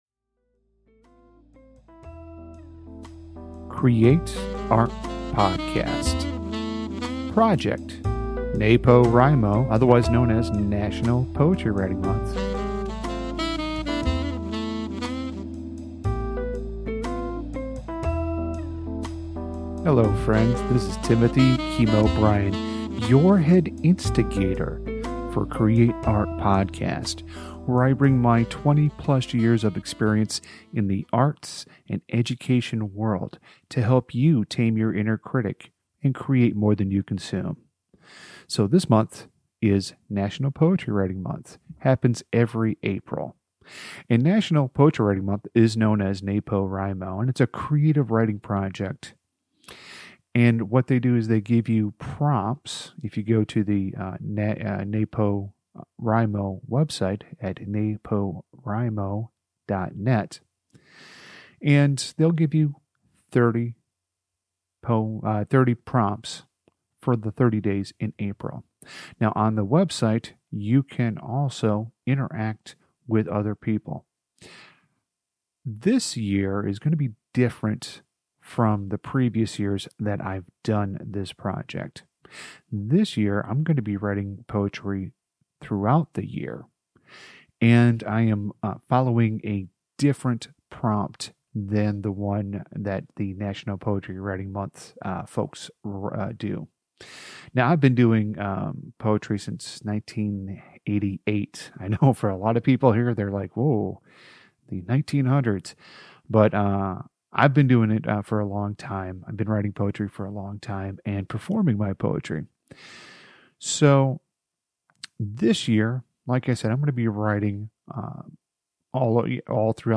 Some of the podcast episodes you will hear will be a live recording of me reading the poem to a live audience, other times I will be reading it in the comfort of my home studio.